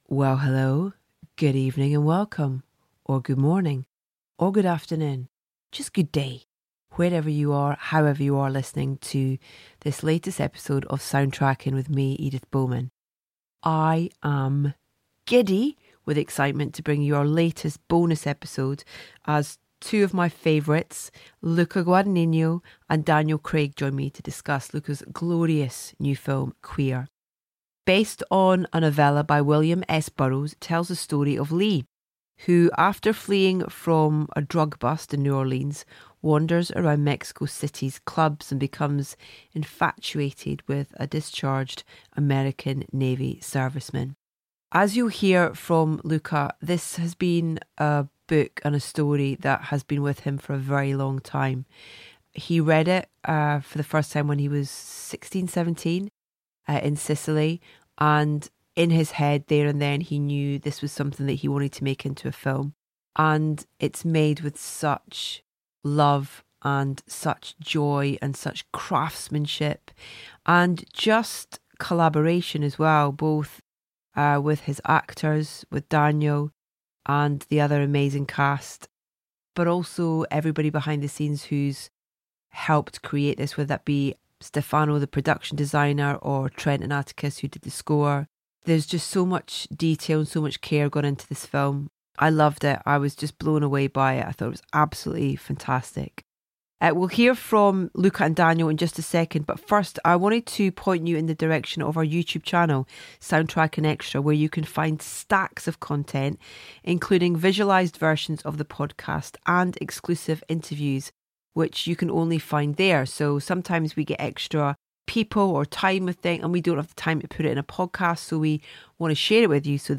We are so excited to bring you our latest bonus episode of Soundtracking, as two of our favourties Luca Guadagnino and Daniel Craig join Edith to discuss Luca's glorious new film, Queer.